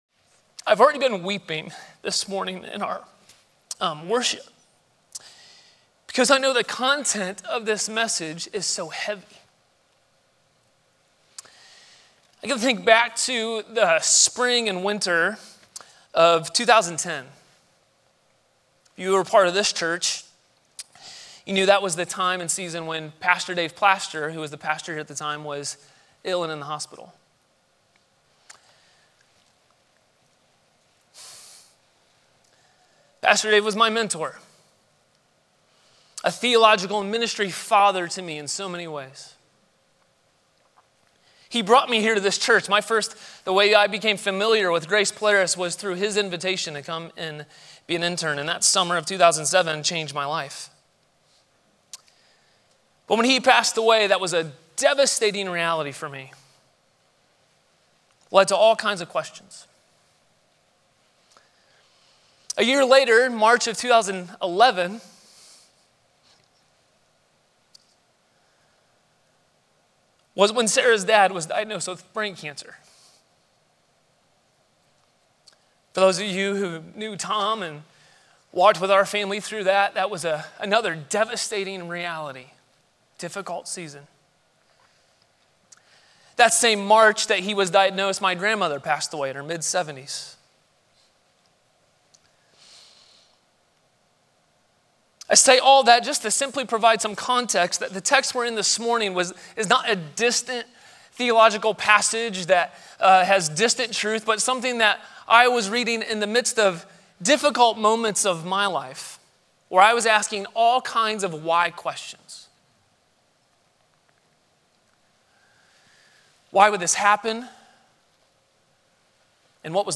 Watch Listen A Common Question Scripture Passage: John 9:1-41